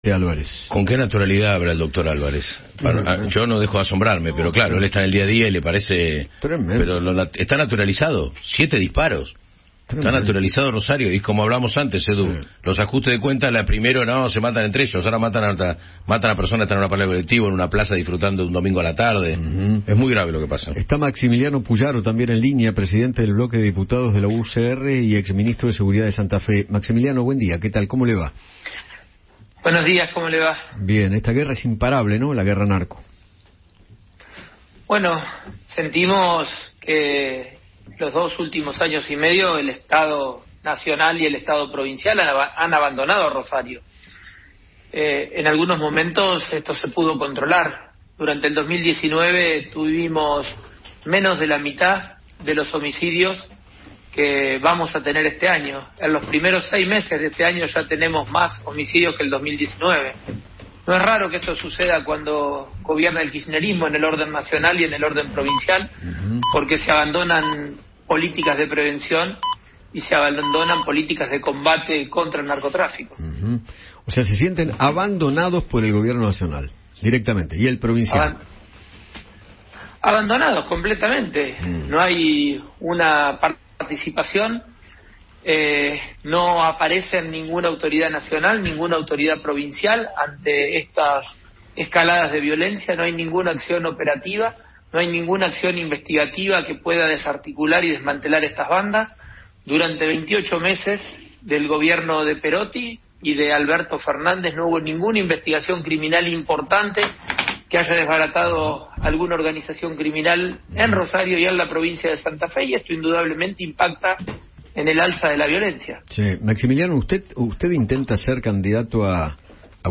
Maximiliano Pullaro, presidente del bloque de diputados de la UCR, dialogó con Eduardo Feinmann sobre el crítico presente de la ciudad santafesina como consecuencia de las bandas narcos.